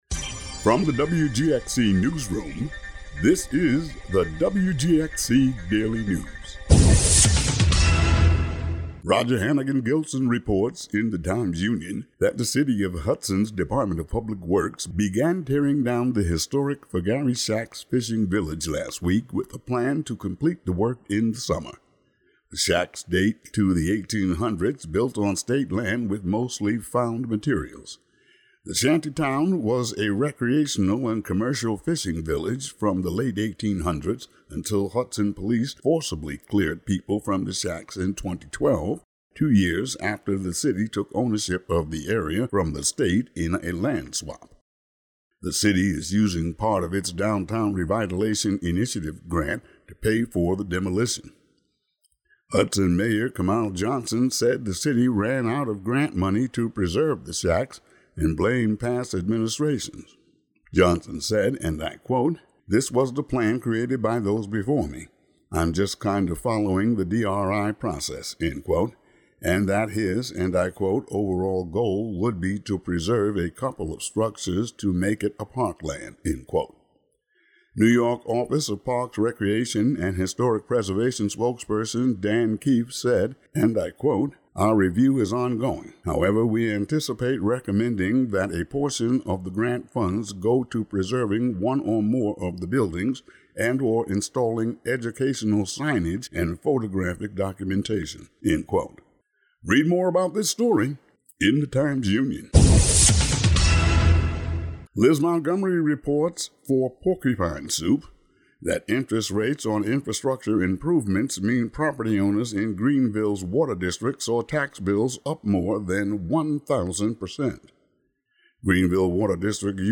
Today's audio daily news update.
Today's daily local audio news.